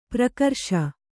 ♪ prakarṣa